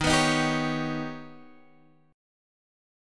Eb+7 chord